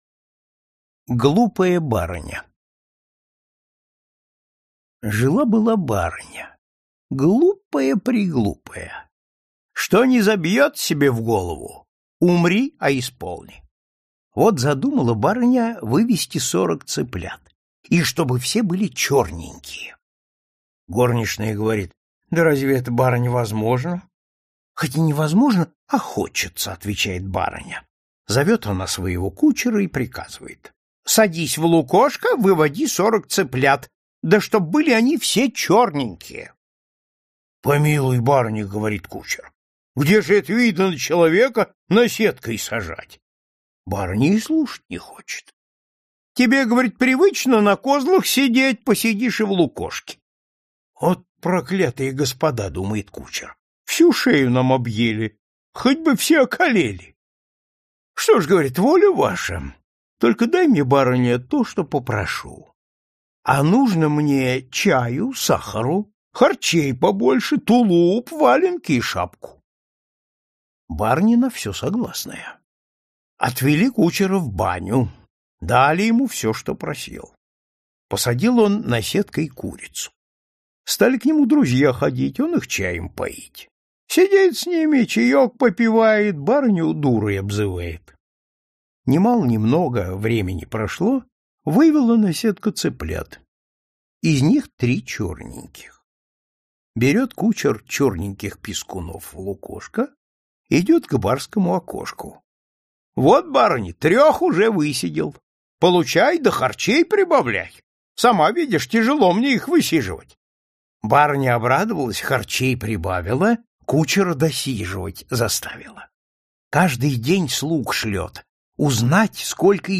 Аудиокнига Бабушкины сказки | Библиотека аудиокниг
Прослушать и бесплатно скачать фрагмент аудиокниги